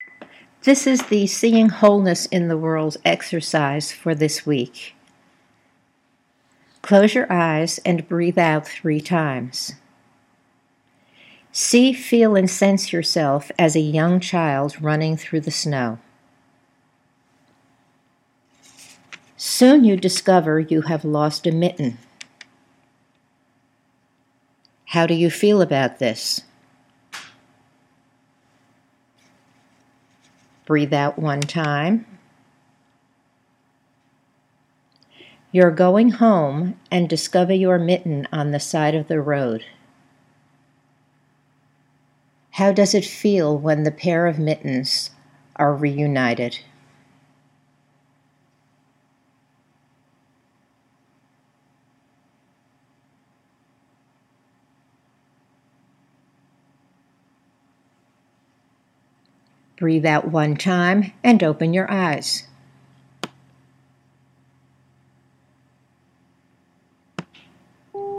Note:  The end of the recording is signified by a light beep.